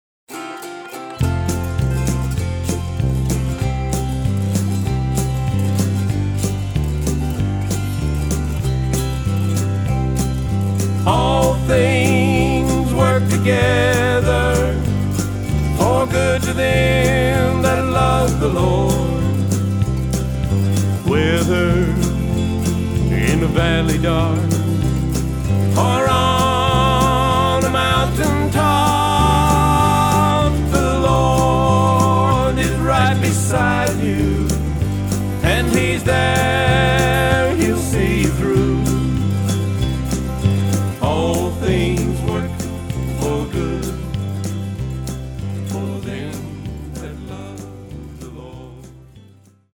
Autoharp, Lead & Harmony Vocals
Drums
Guitar
Keyboards, Bass Vocals
Mandolin
Fiddle